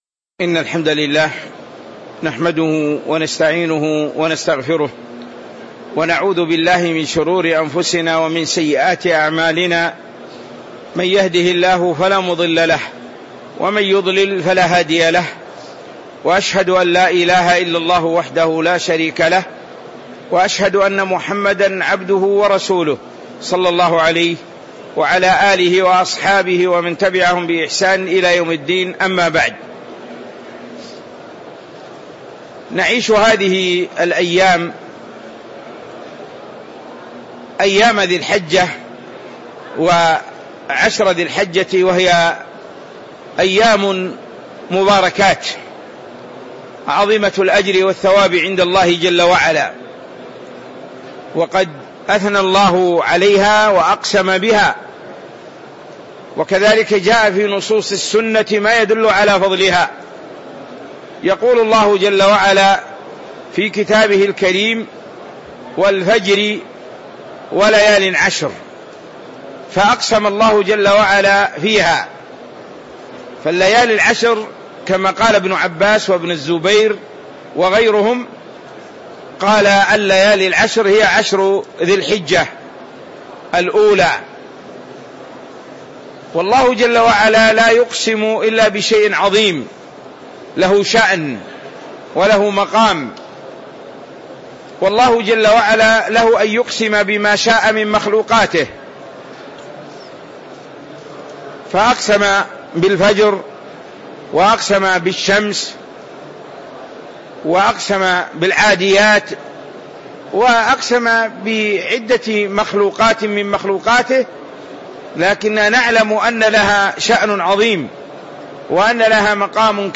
تاريخ النشر ٥ ذو الحجة ١٤٤٦ هـ المكان: المسجد النبوي الشيخ